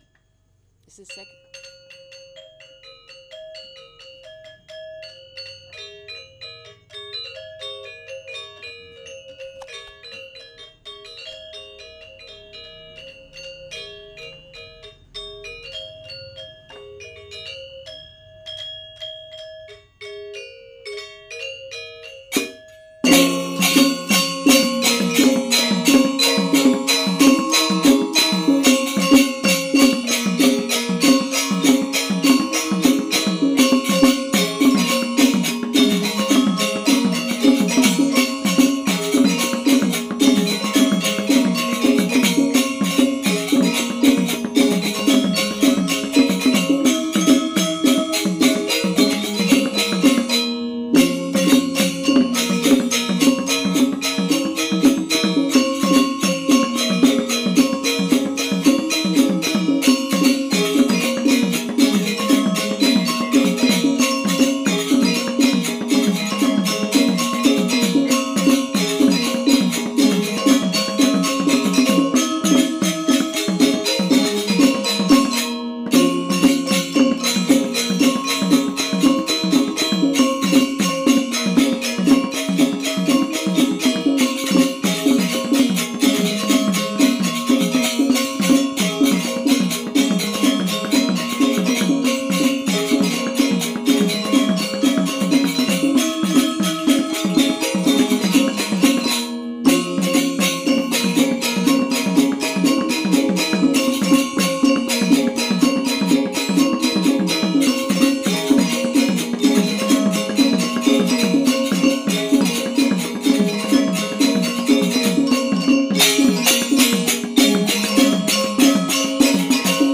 Balinese Gamelan Angklung Cremation Music